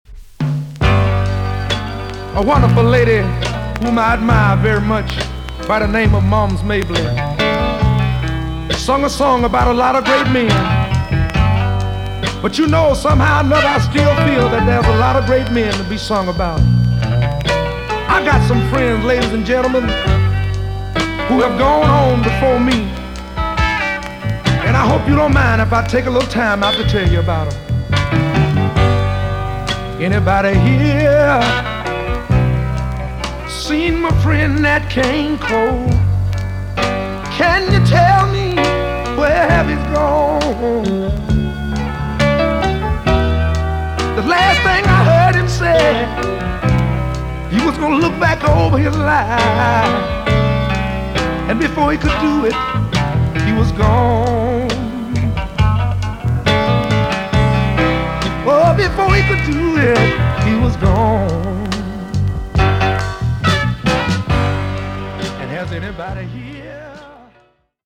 EX 音はキレイです。
NICE NORTHERN SOUL TUNE!!